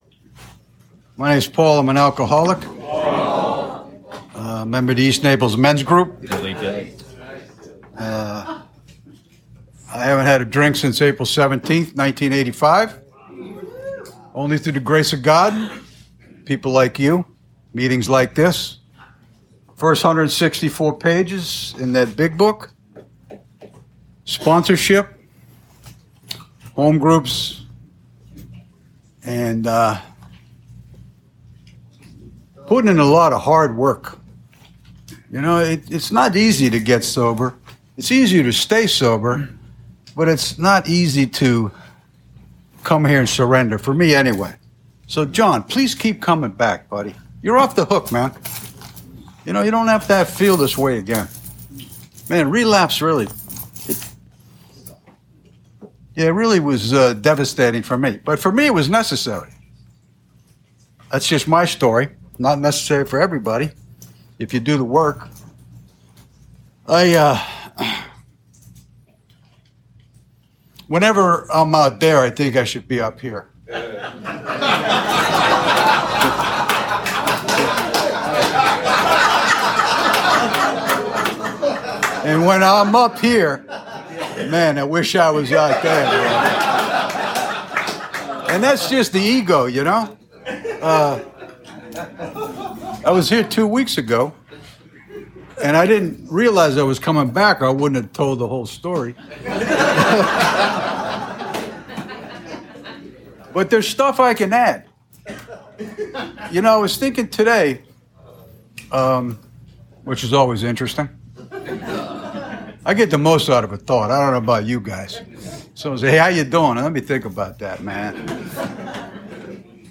Alcoholics Anonymous Speaker Recordings
at The Fort Lauderdale 12 Step Group, Twelve Step House Ft. Lauderdale, FL.